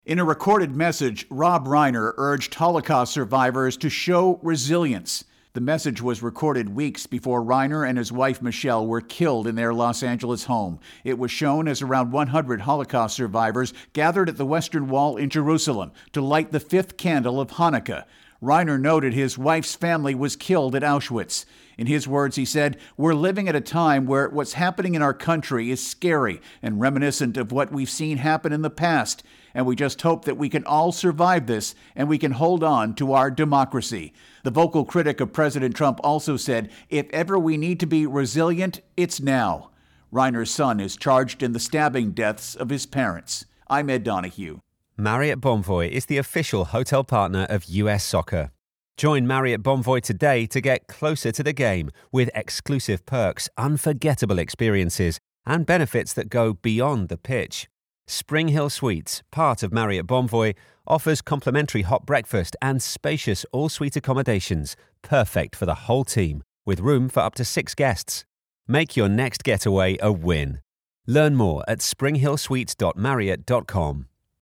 In recorded message, Rob Reiner urges resiliency for Holocaust survivors at Hanukkah event